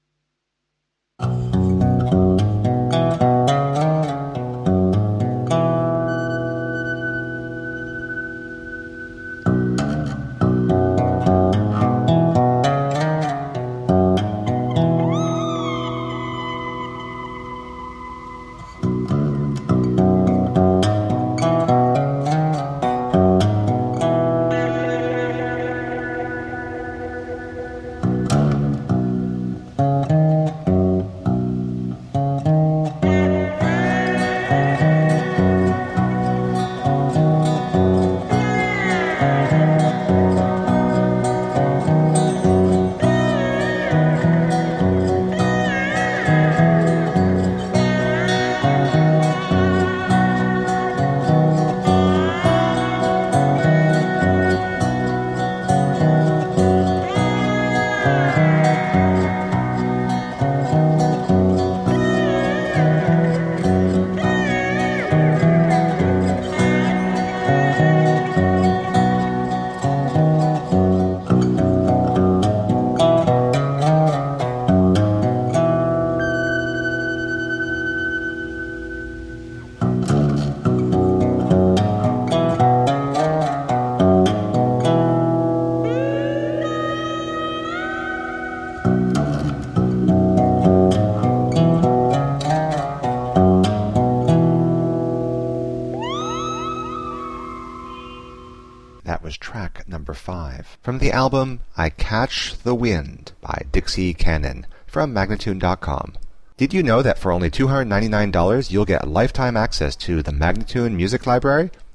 Tagged as: Alt Rock, Pop, Classic rock, Prog Rock